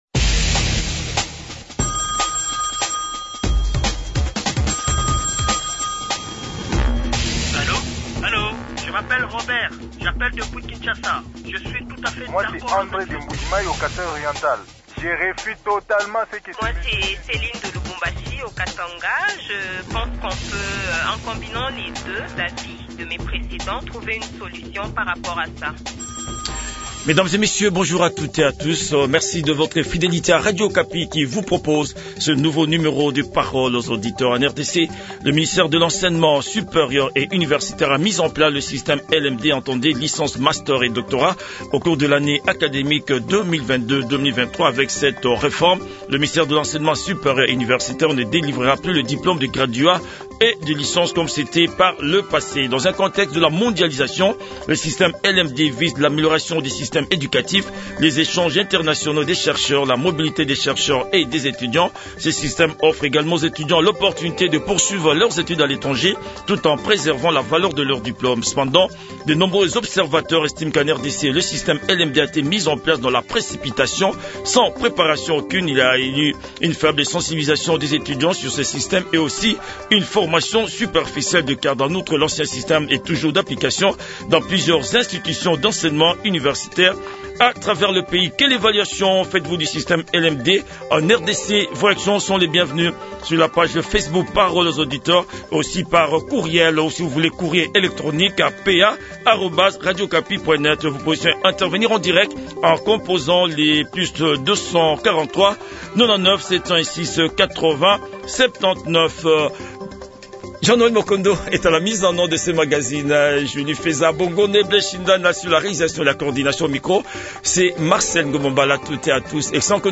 Le débat citoyen a été engagé entre nos auditeurs et nos 2 invités